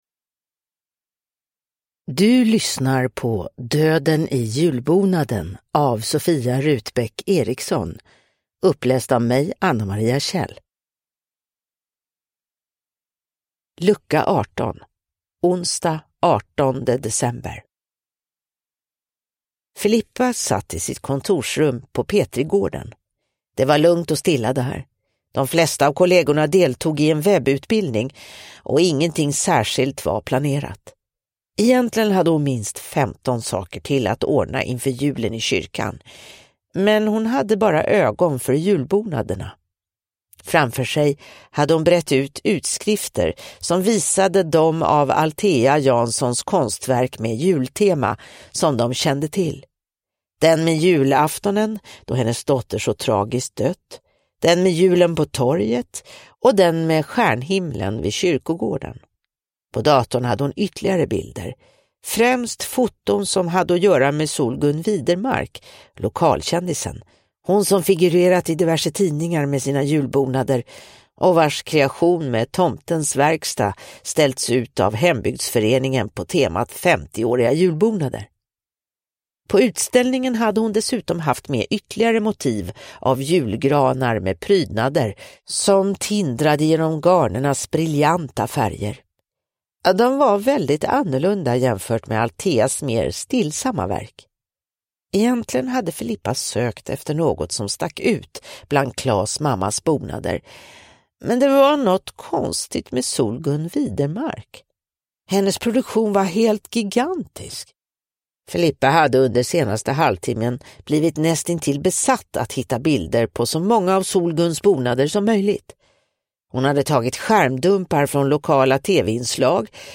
Döden i julbonaden: Lucka 18 – Ljudbok